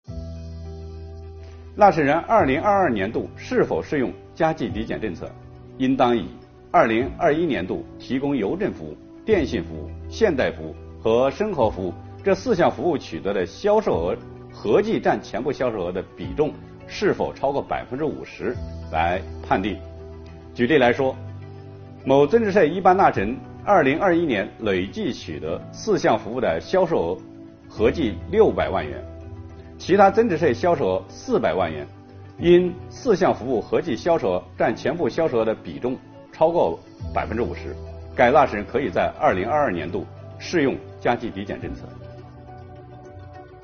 近日，国家税务总局推出新一批“税务讲堂”系列课程，为纳税人缴费人集中解读实施新的组合式税费支持政策。本期课程由国家税务总局货物和劳务税司副司长刘运毛担任主讲人，对2022年服务业领域困难行业纾困发展有关增值税政策进行详细讲解，方便广大纳税人更好地理解和享受政策。